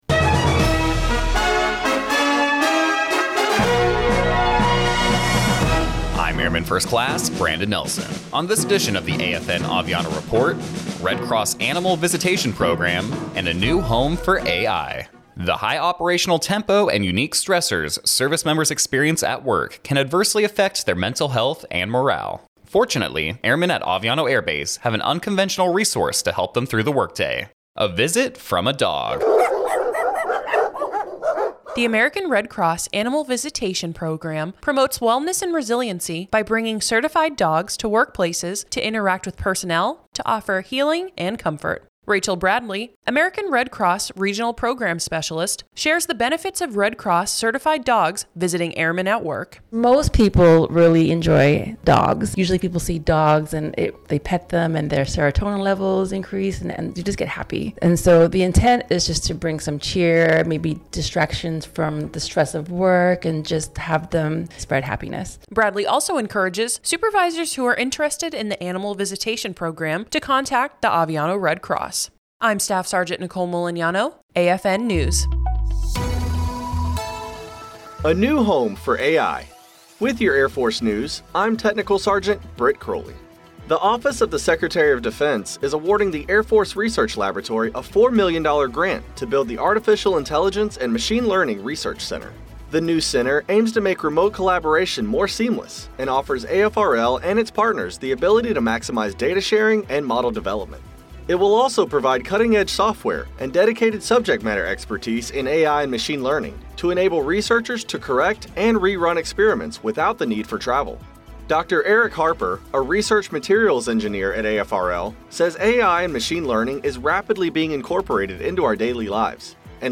American Forces Network (AFN) Aviano radio news reports on the American Red Cross Animal Visitation Program and how visits from dogs in the workplace can help build morale and relieve stress.